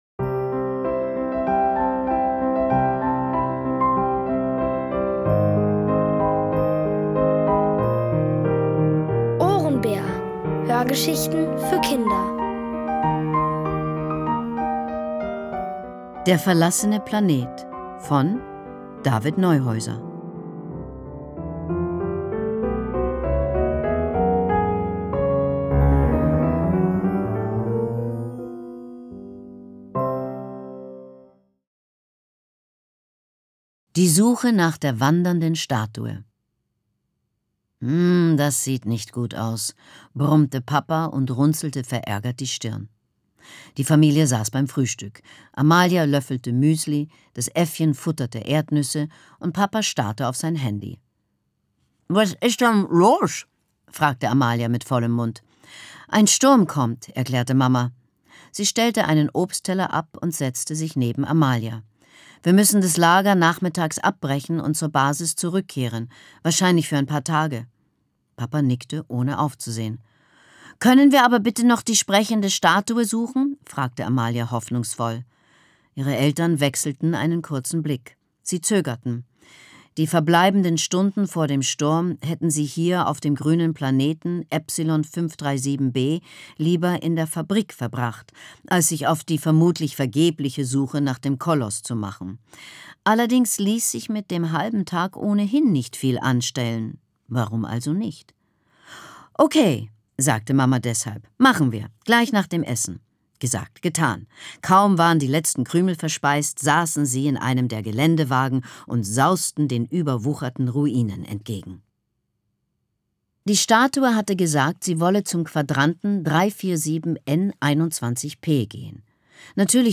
Es liest: Leslie Malton.